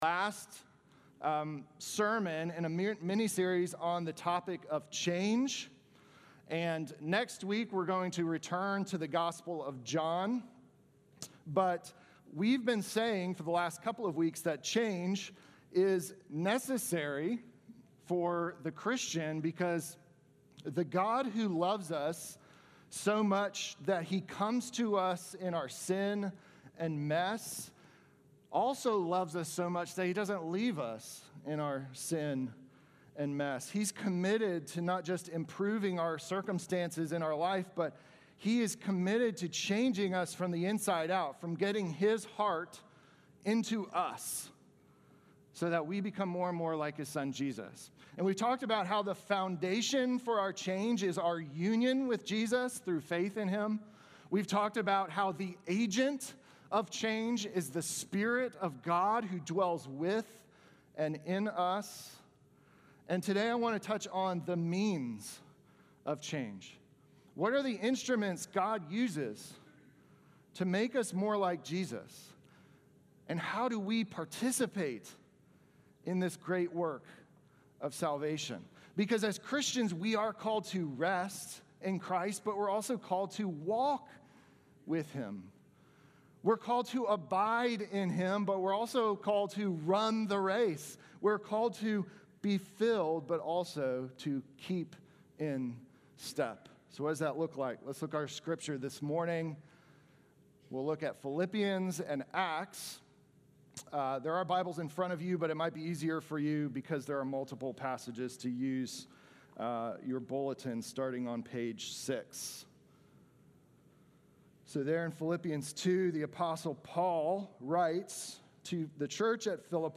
Sermon from August 10